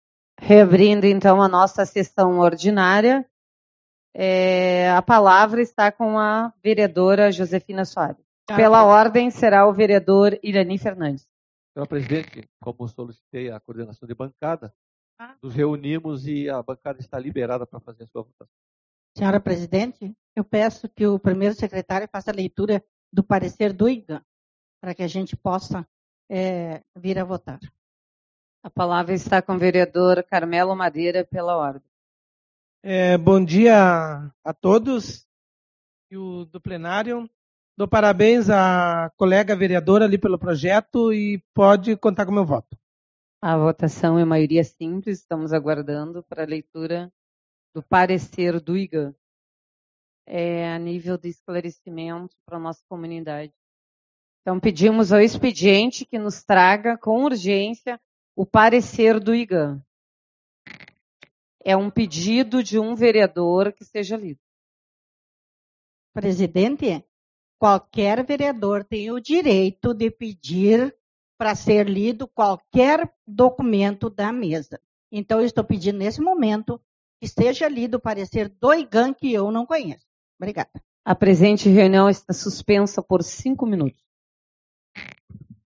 23/04 - Reunião Ordinária